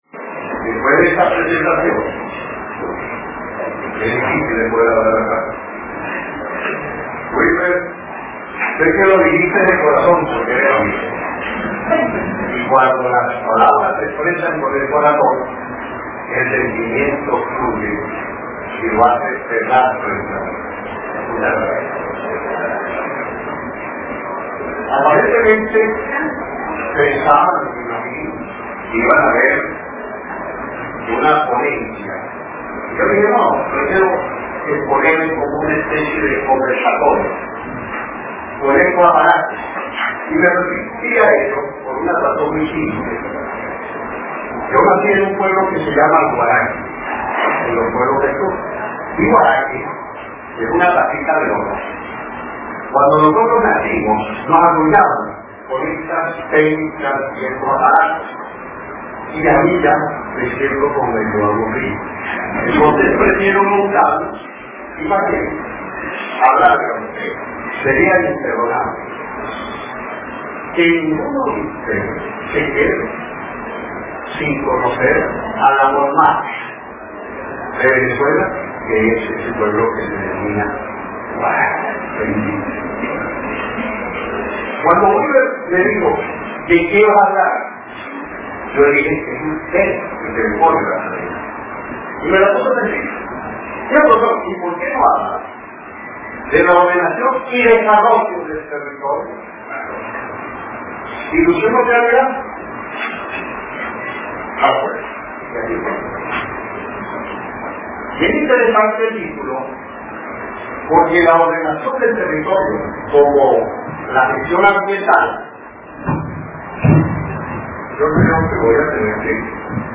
Ponencias